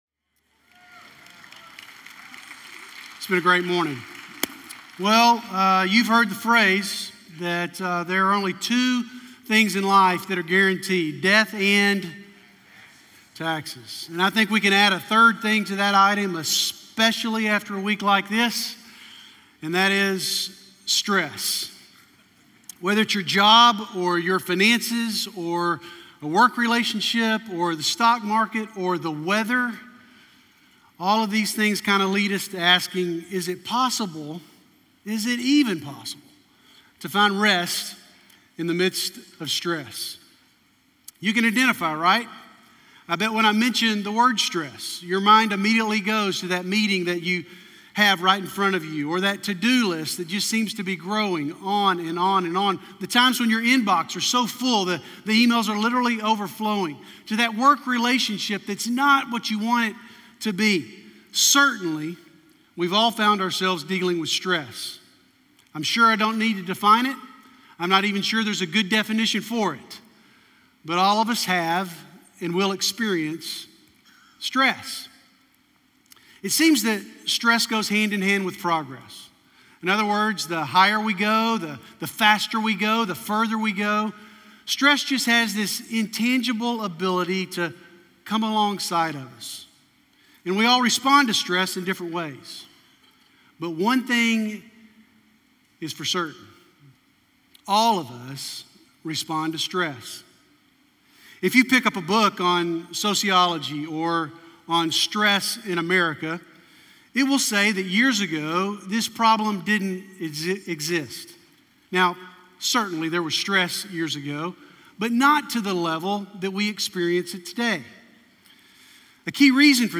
Join us Sundays for Christ-centered worship, Bible teaching, life groups, and kids and student ministries.